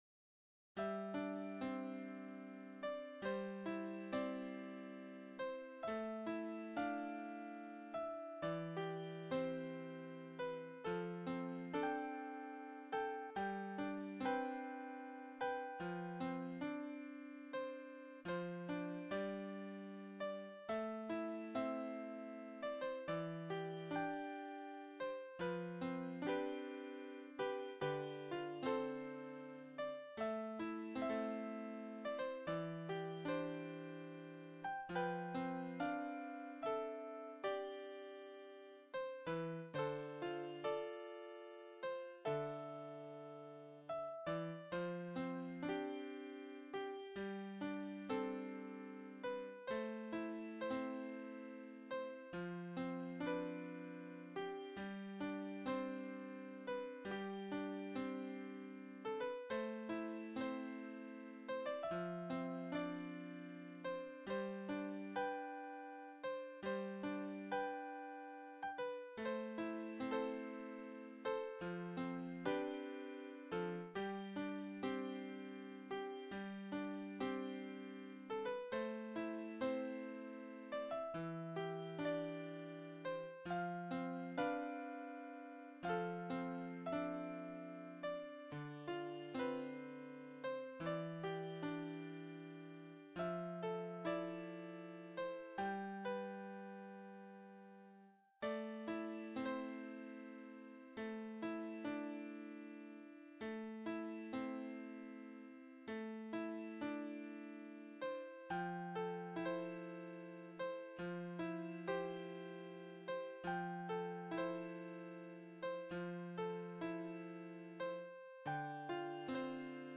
Another piano solo melody